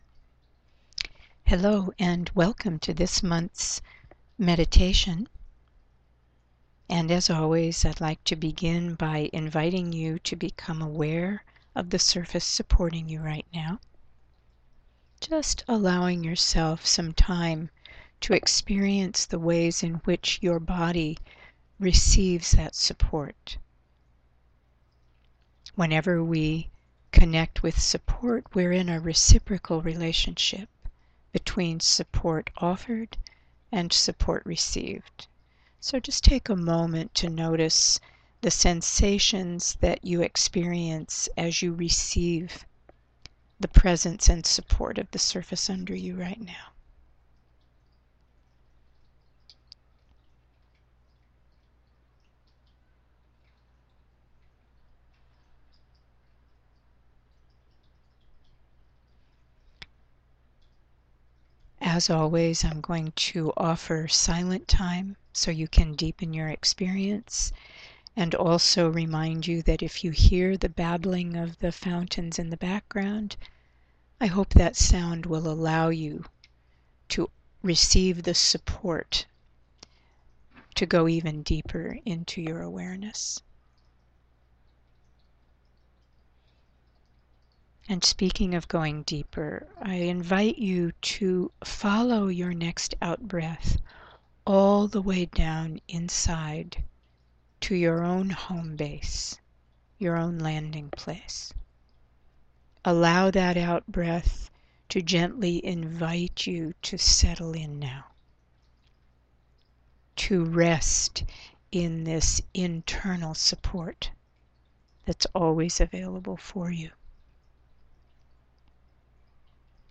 Please remember never to listen to guided audio meditations while driving or using dangerous machinery.